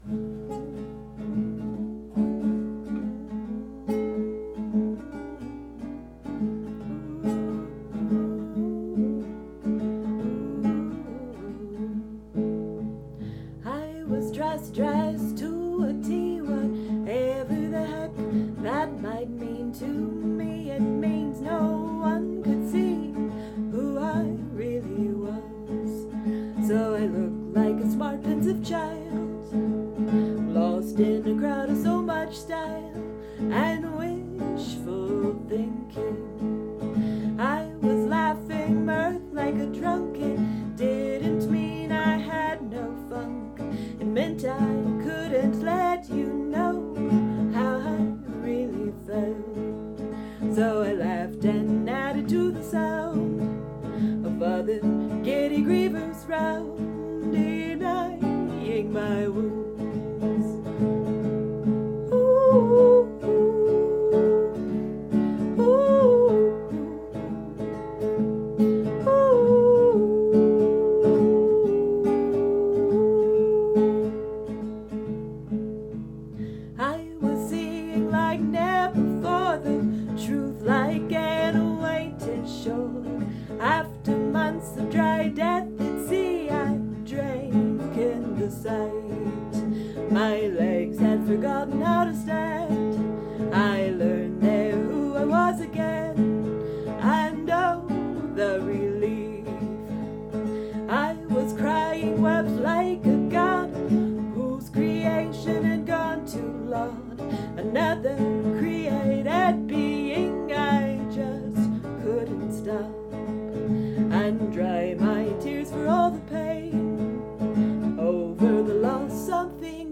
first or second capo